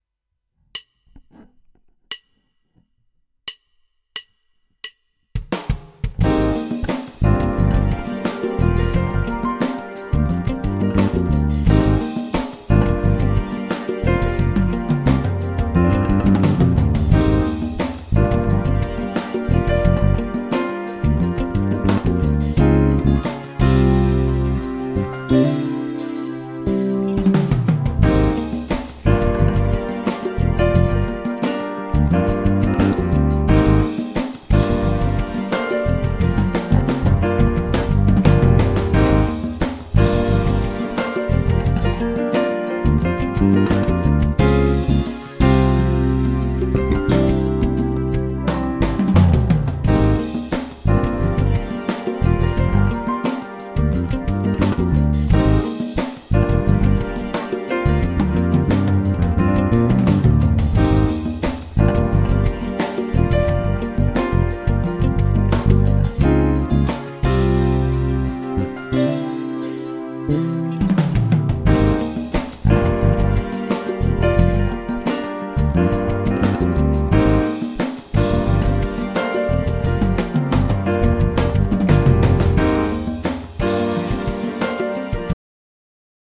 Ukázka 4 - oba snímače, všechno narovno.